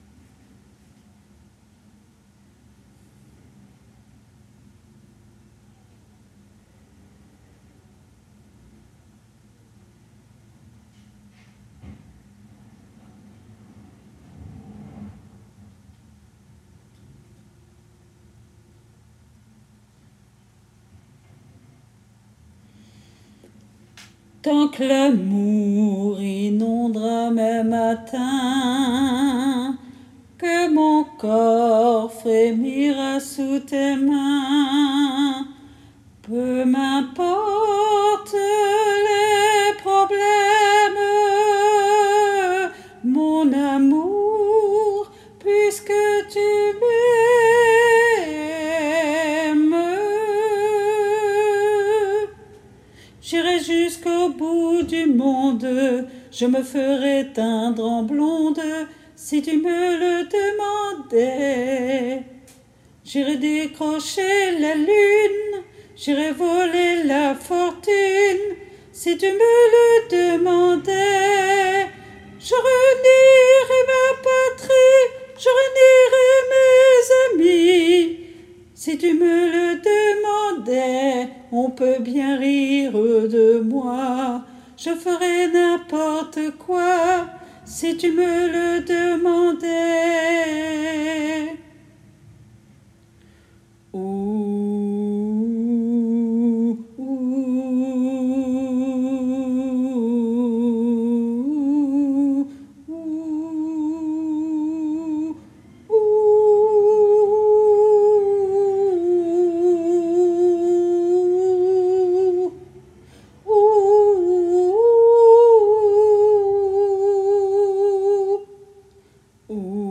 MP3 versions chantées
Basse